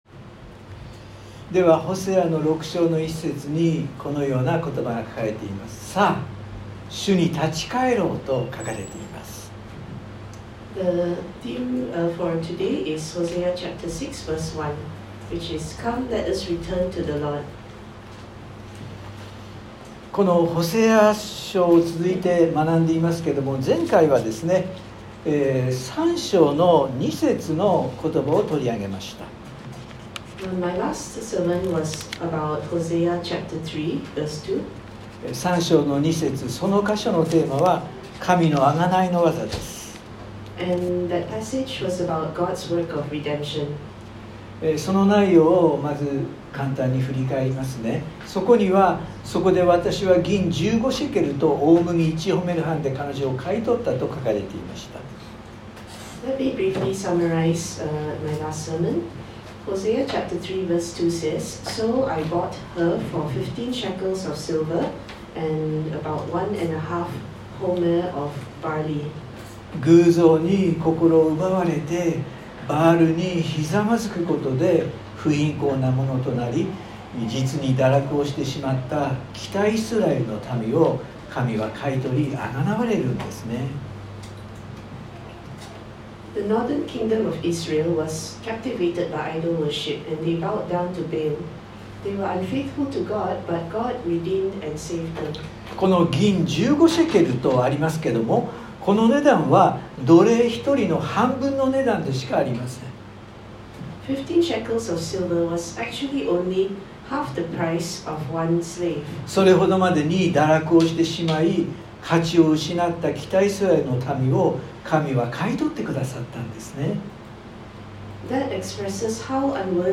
（日曜礼拝録音） 【iPhoneで聞けない方はiOSのアップデートをして下さい】 前回はホセア書3章２節の御言葉を取り上げました。